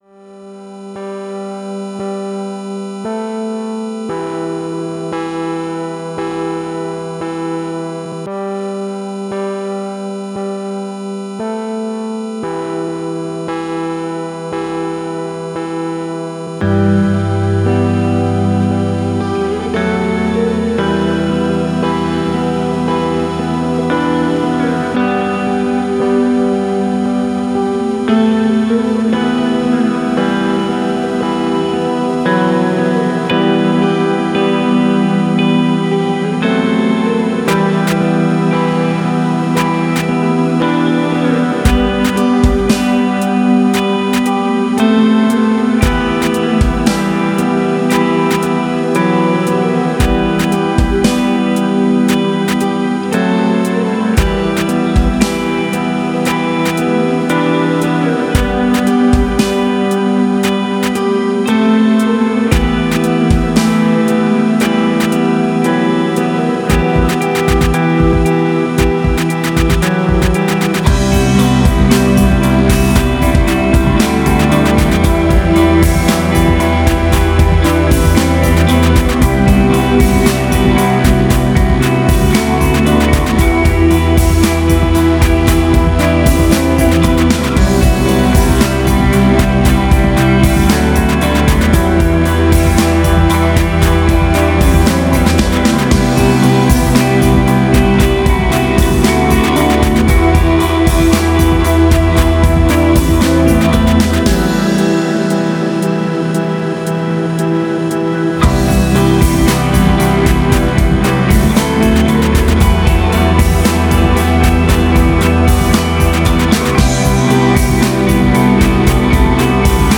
instrumental classical Post-Rock to the electronic music